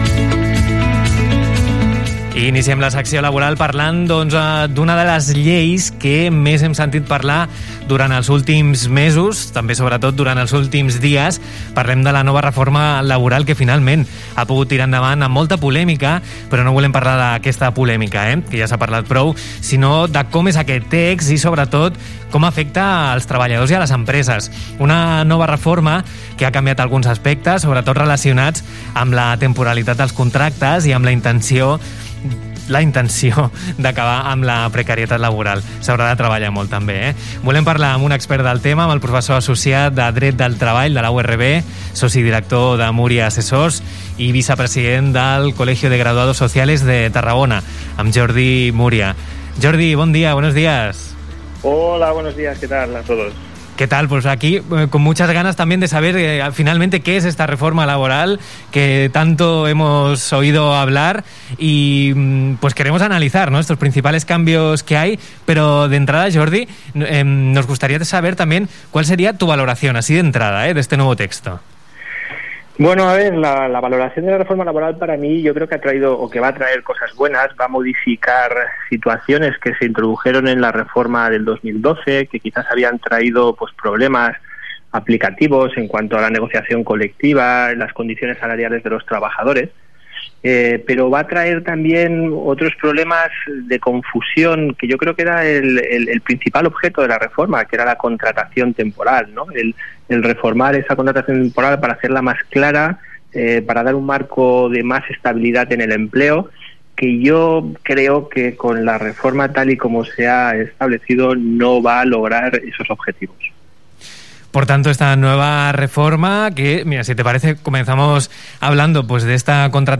Podcast entrevista Tarragona Radio - Reforma Laboral
0:00 Entrevista Reforma Laboral Tarragona Radio